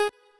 left-synth_melody04.ogg